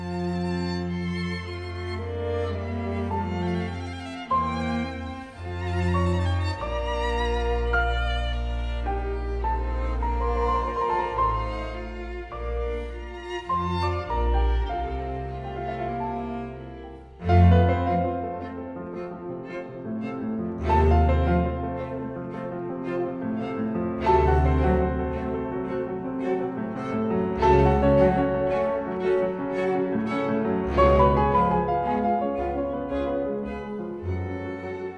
Струнные и рояль
Жанр: Классика